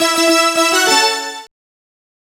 Synth Lick 49-09.wav